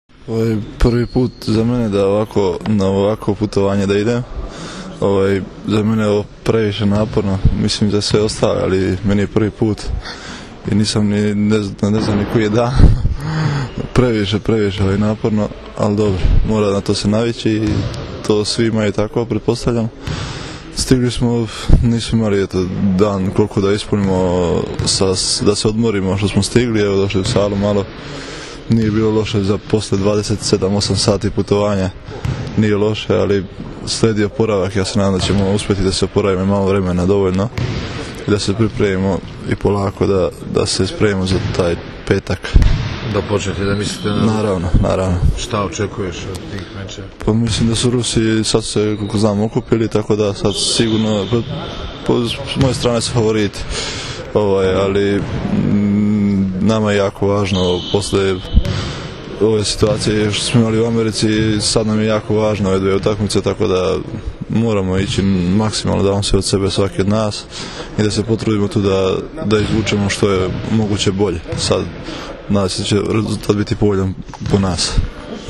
Pogača i so u rukama devojaka obučenih u rusku narodnu nošnju dočekali su, posle dugog puta iz Čikaga, seniore Srbije, uz TV ekipe koje su čekale da intervjuišu reprezentativce Srbije i članove stručnog štaba.
IZJAVA MARKA IVOVIĆA